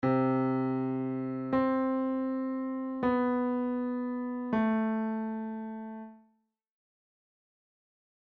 Here's another example, this time with C and C
then two different white notes